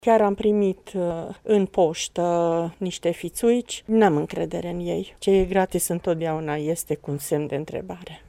Mureșenii au auzit de caravanele optometrice, dar nu au încredere în ele: